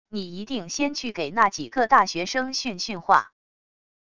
你一定先去给那几个大学生训训话wav音频生成系统WAV Audio Player